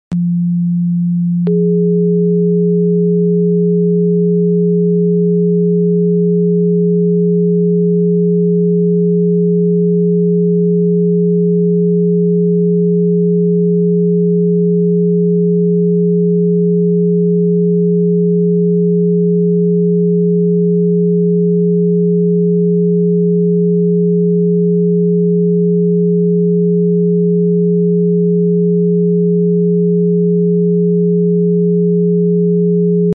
☯ 174 + 417 Hz→ sound effects free download